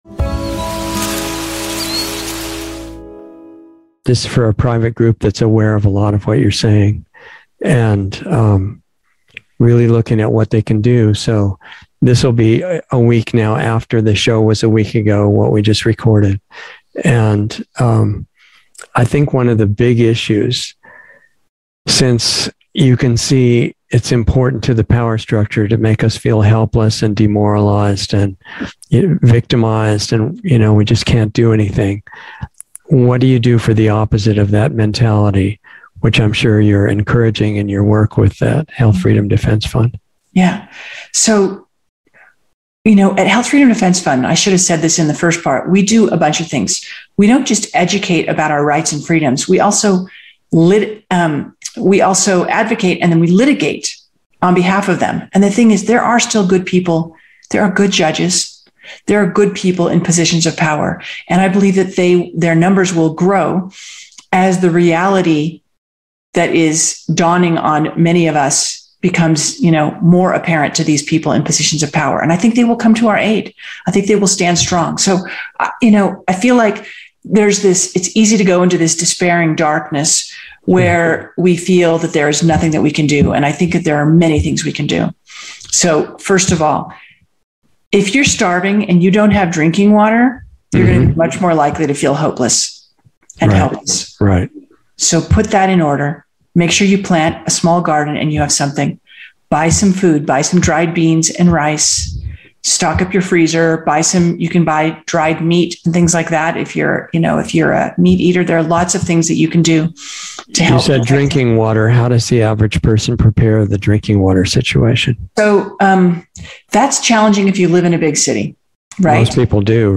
Insider Interview 5/25/22